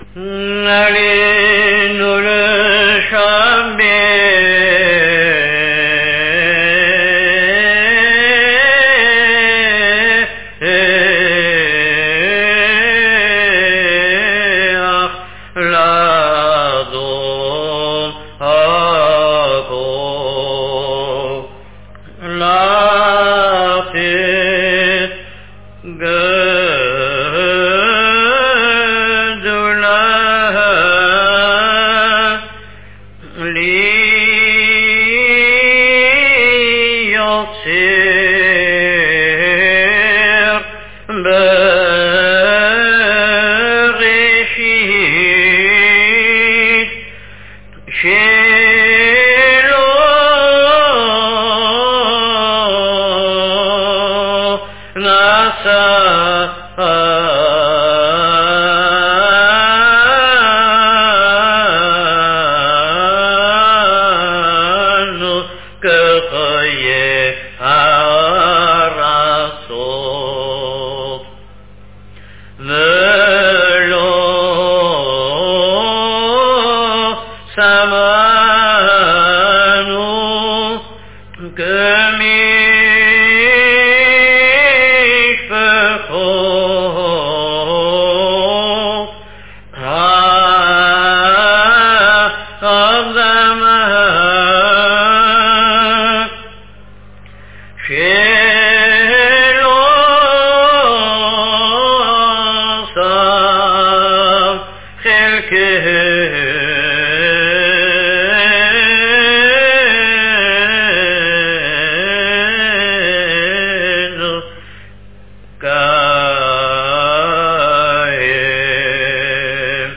Mind that most recordings were made by non professional Chazzanim and thus are not 100% according to the sheet music.